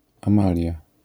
wymowa:
IPA[ãˈmalʲja], AS[ãmalʹi ̯a], zjawiska fonetyczne: zmięk.nazal.i → j